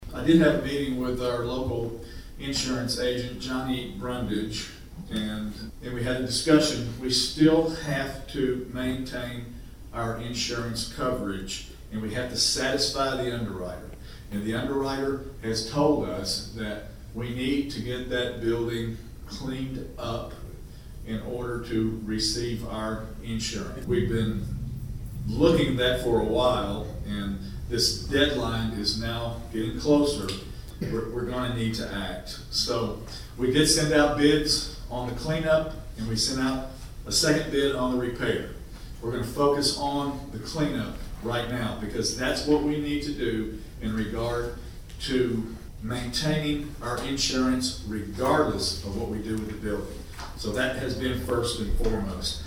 At Monday’s Martin City Board, Mayor David Belote explained why clean up must occur soon.